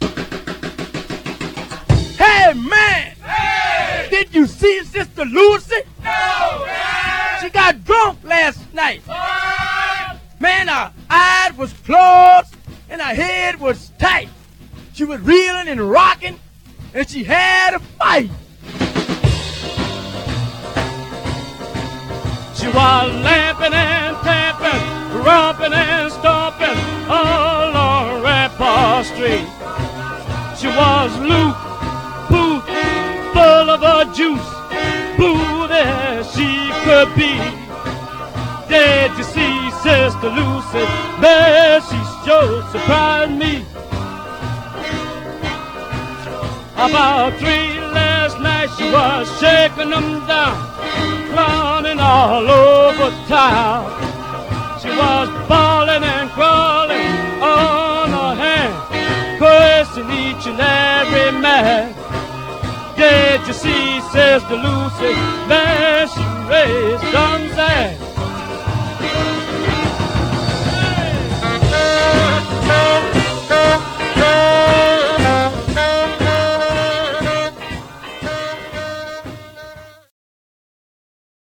Surface noise/wear
Mono